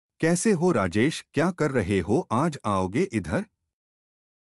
mp3-output-ttsfree(dot)com.wav